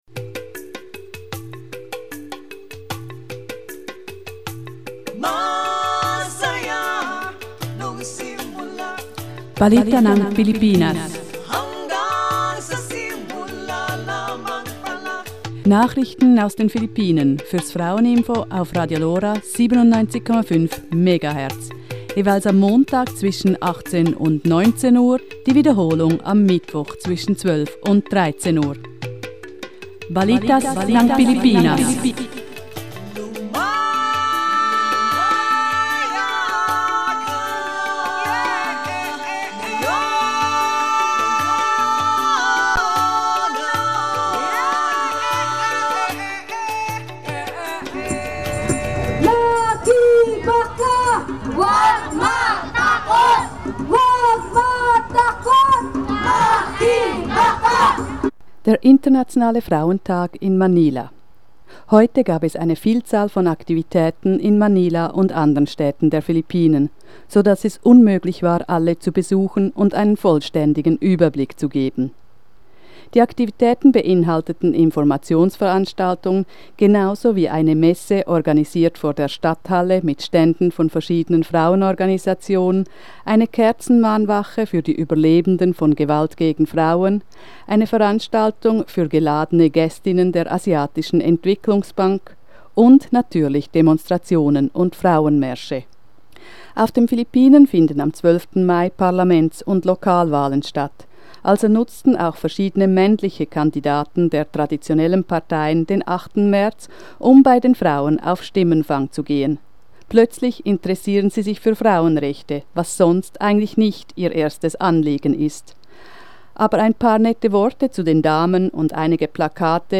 8. März in Manila, Philippinen: Eindrücke der Aktionen und Demonstrationen